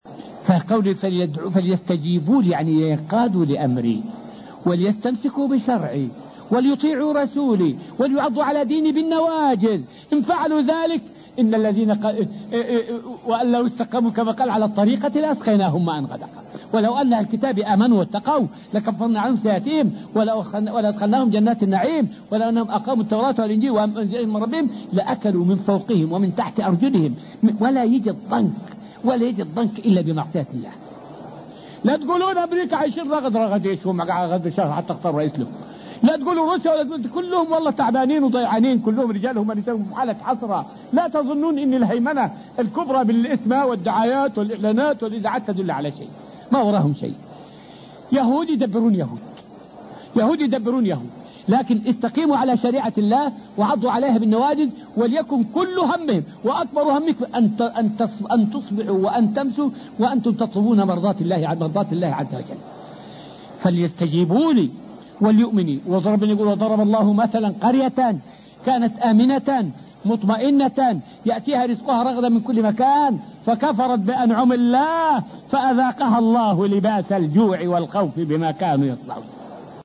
فائدة من الدرس الخامس والعشرون من دروس تفسير سورة البقرة والتي ألقيت في المسجد النبوي الشريف حول أنه لا إيمان لمن لم يؤمن بمحمد عليه السلام.